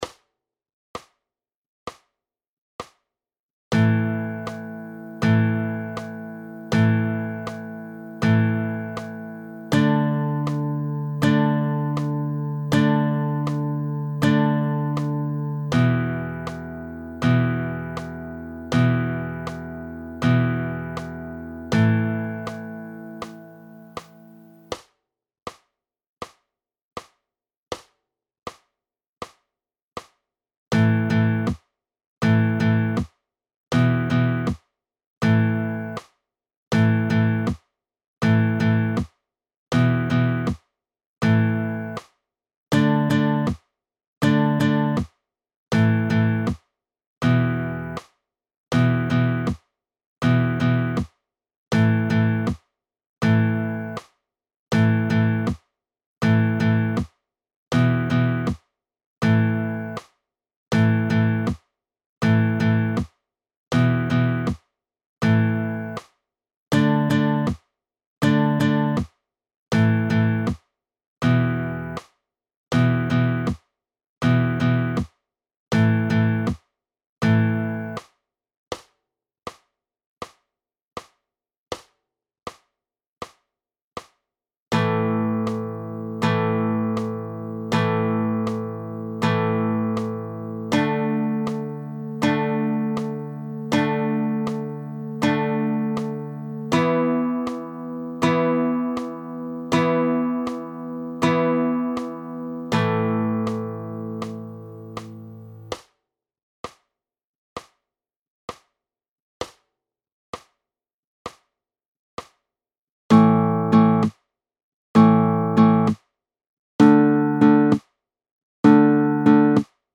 II. Powerchords: A5, D5, E5 – 3stimmig: PDF
+ Audio (80/65 bpm):